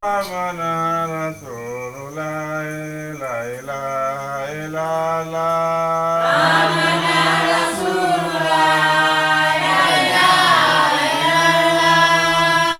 VOC 02.AIF.wav